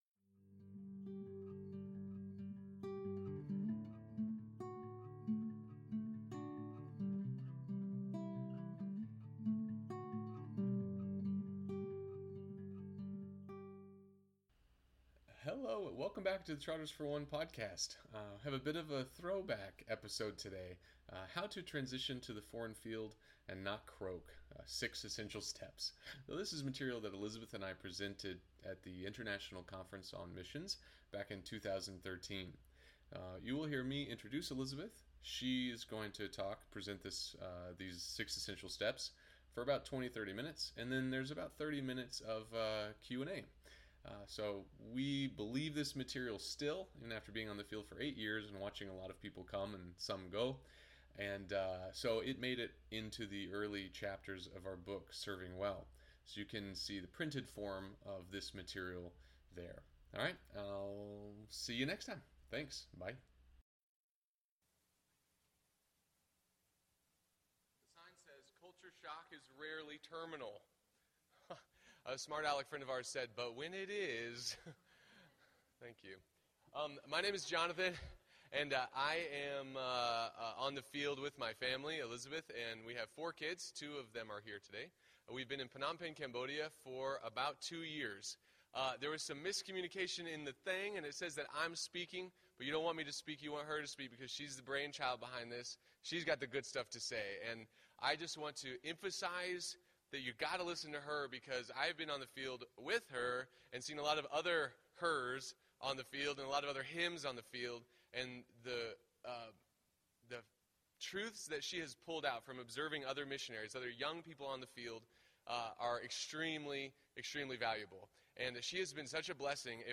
We presented this workshop at the International Conference on Missions way back in 2013. At the end of the workshop there are about 30 minutes of Q&A. Listen in on iTunes or Stitcher.